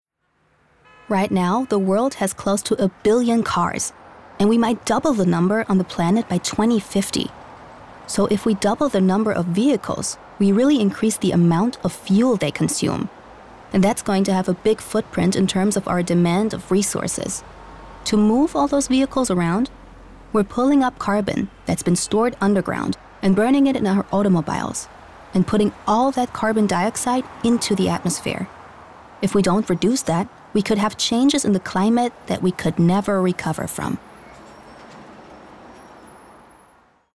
Comment (Kommentar), Doku, Commercial (Werbung), Presentation
English US, English NEUTRAL